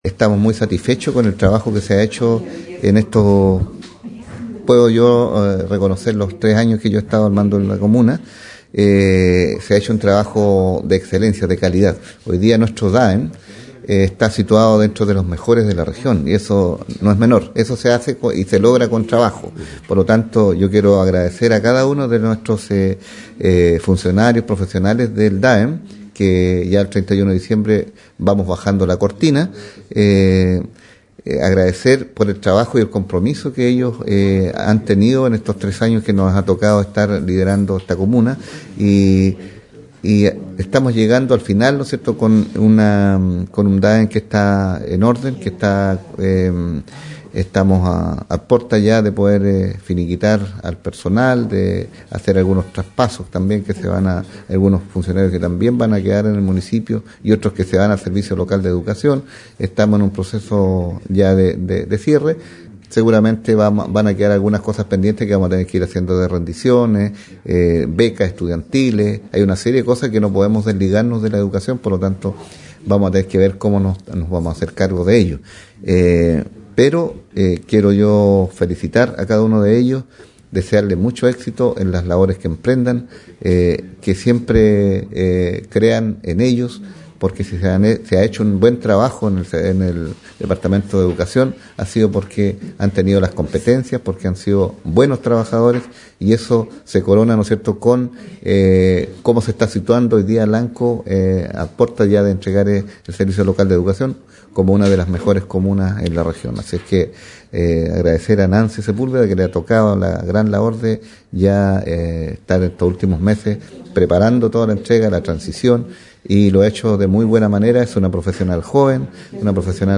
En el marco del traspaso de la educación municipal al Servicio Local de Educación Pública (SLEP) Valdivia, las comunidades escolares de la comuna de Lanco se reunieron en el Teatro Galia para despedir al equipo de funcionarios del Departamento Administrativo de Educación Municipal (DAEM).
El alcalde Juan Rocha expresó su gratitud hacia el equipo del DAEM, calificándolo como uno de los departamentos más eficientes y ordenados de la región.